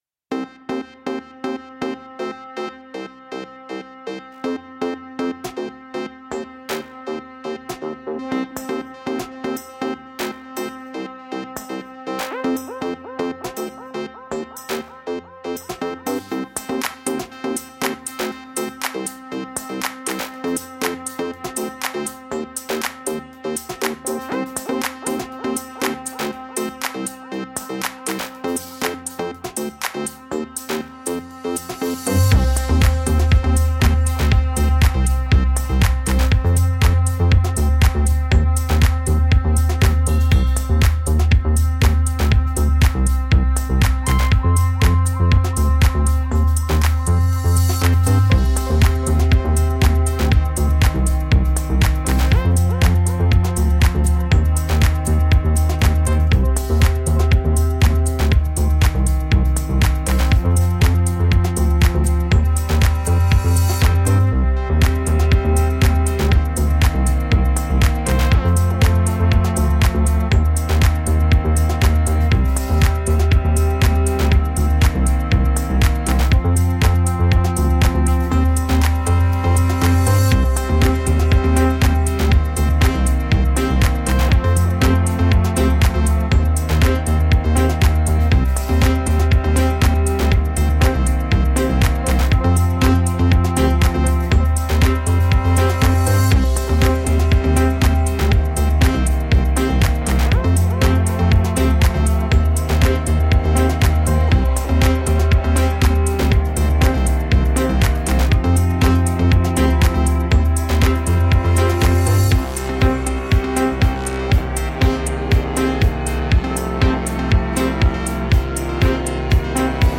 Highly danceable electro beats with virtuouso cello.